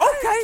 SouthSide Chant (14).wav